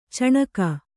♪ caṇaka